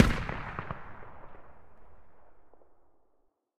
添加小型炮弹实体和lav150开火动画及音效
lav_veryfar.ogg